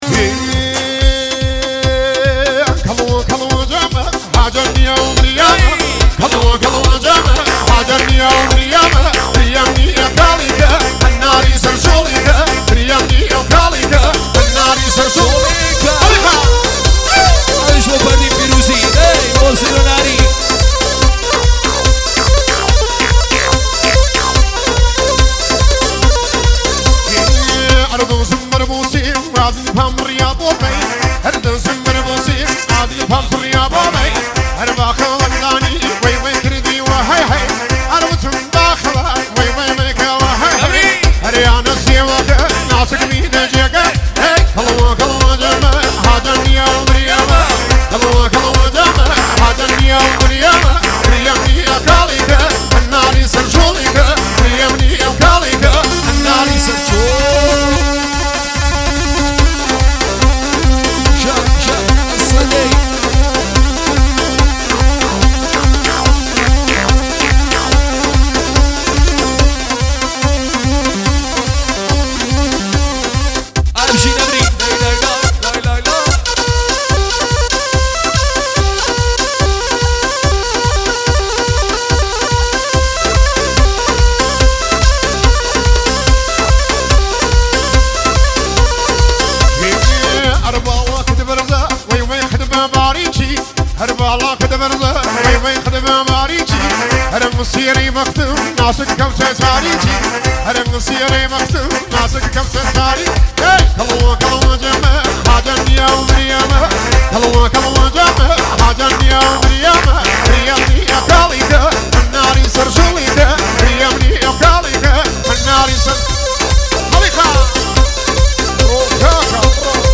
آهنگ های شاد کردی